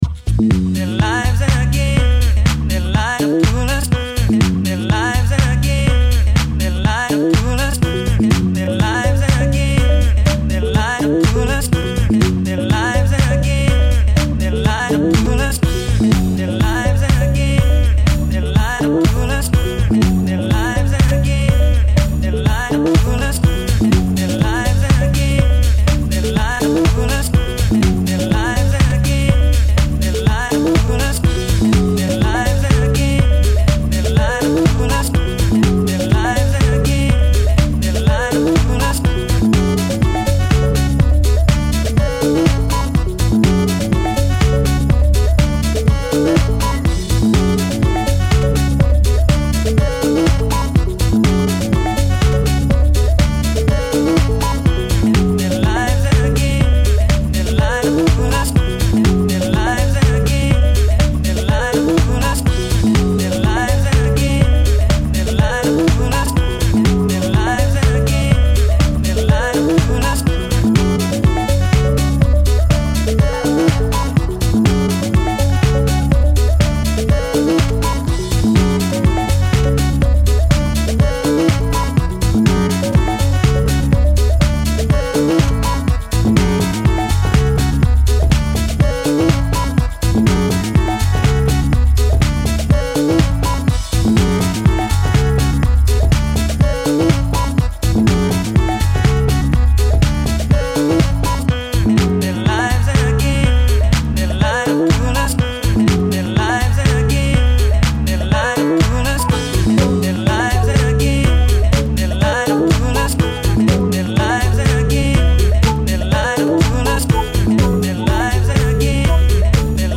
this sounds like an airplane landing on a minefield